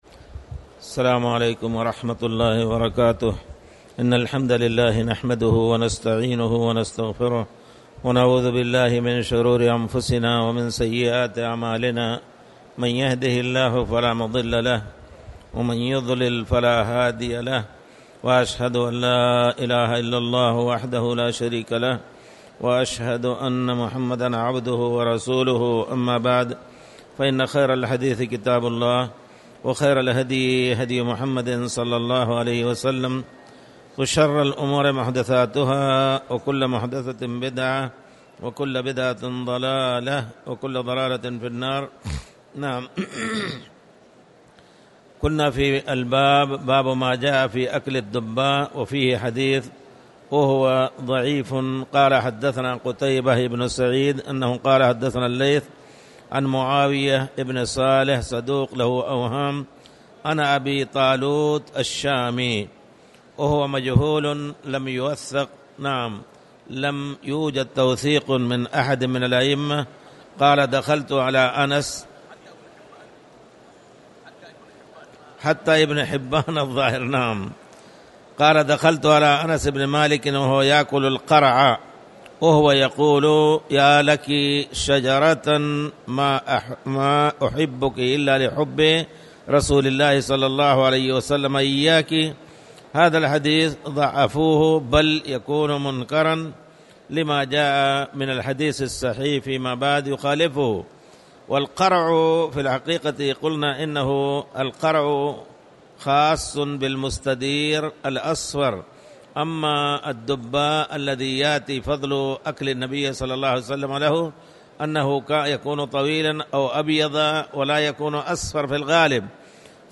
تاريخ النشر ١٢ شوال ١٤٣٨ هـ المكان: المسجد الحرام الشيخ